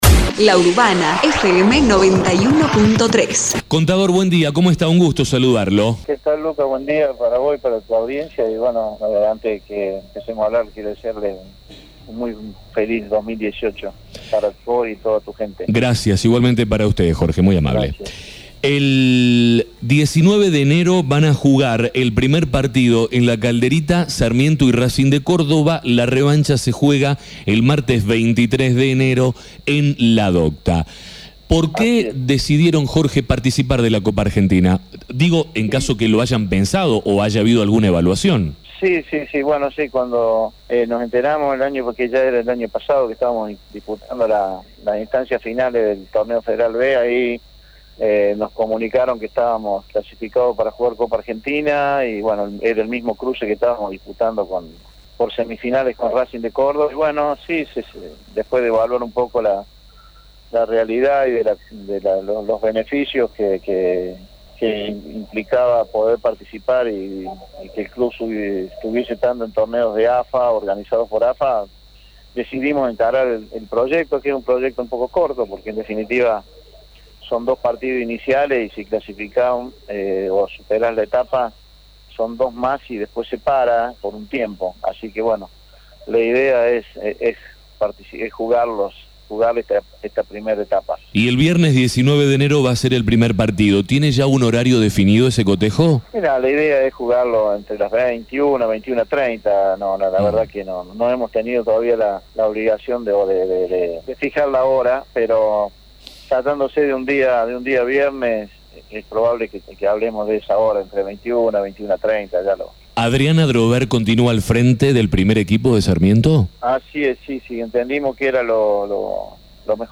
En la entrevista